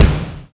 45 KICK 2.wav